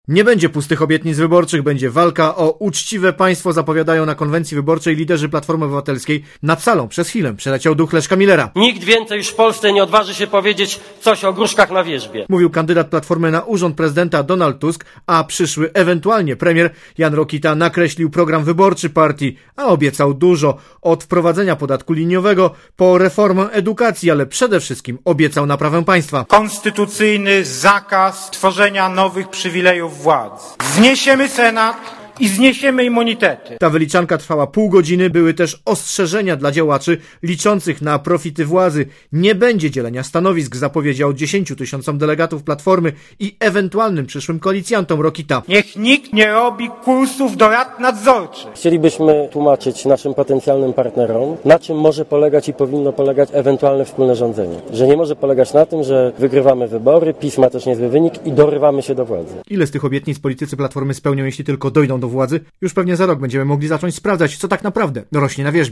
Rekordowa była frekwencja na warszawskim Torwarze i równie imponująco brzmiały wystąpienia polityków.